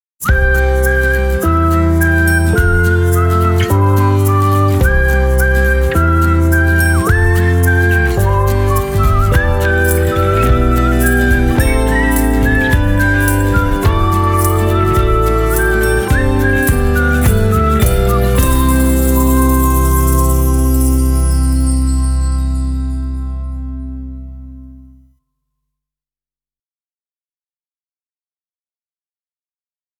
口笛ソング / ジングル